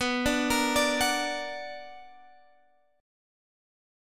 BmM7 chord